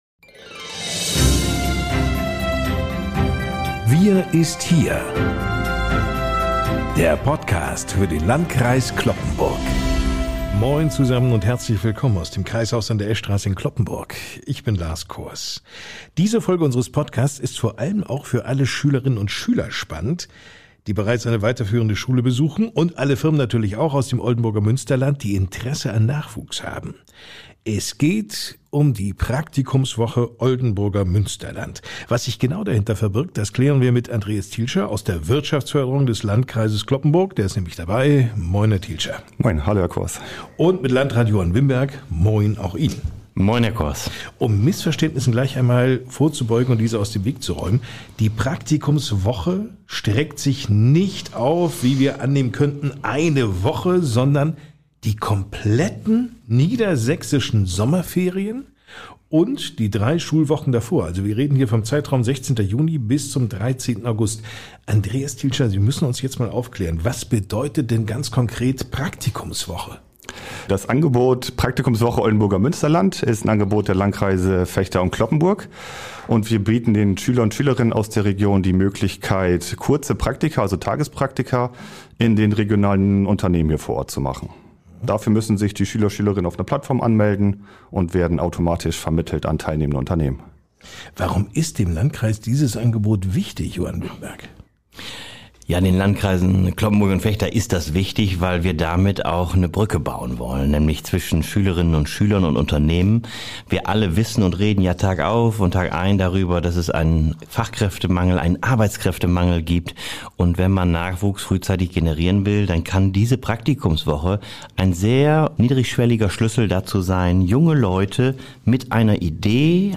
Dabei als Gesprächspartner stets an seiner Seite: Landrat Johann Wimberg.